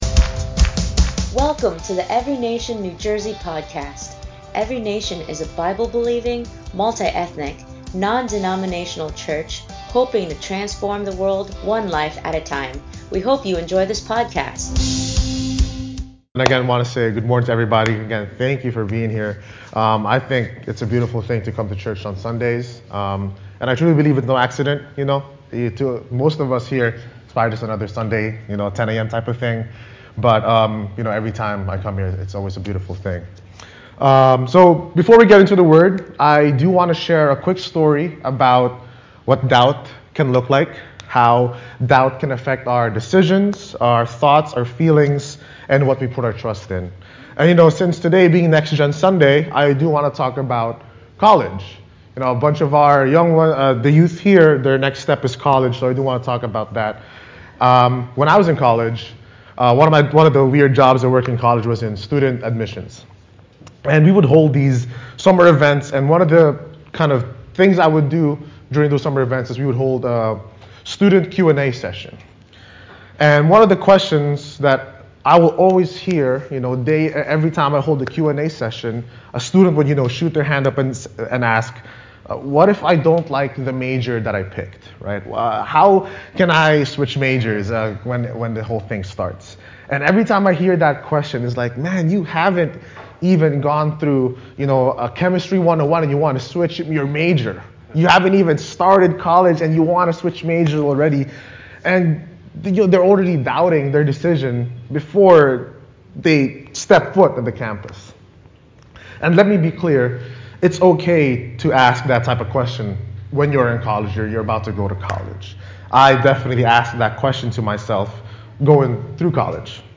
ENCNJ Sermon